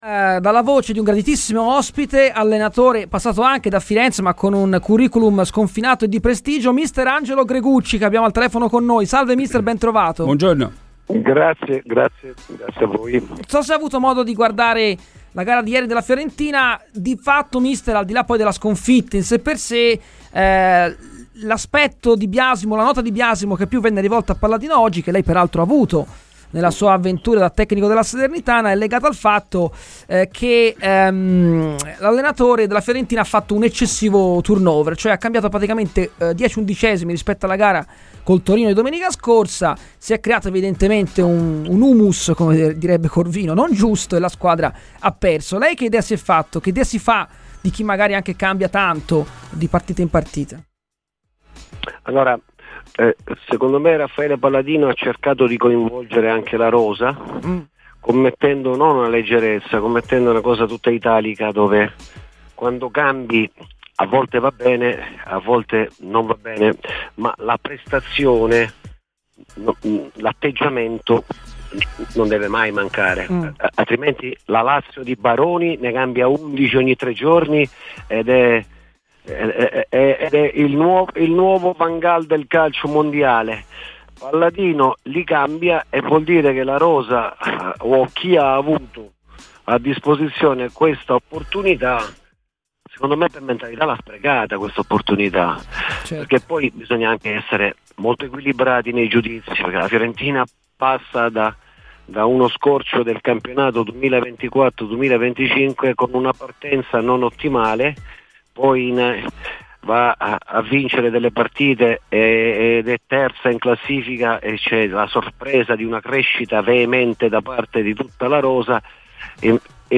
E' la mente che fa la differenza e queste squadre ormai hanno alzato tutte il livello di gioco" ASCOLTA L'INTERVISTA COMPLETA DAL PODCAST